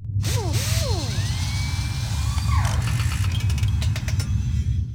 propellersRepair.wav